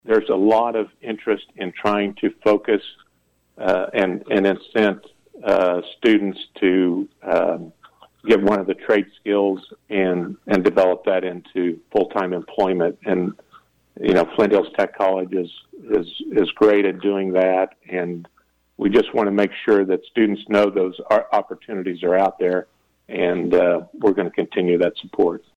Following the wrap-up of the regular session last week, Schreiber joined KVOE’s Morning Show Wednesday to offer his thoughts.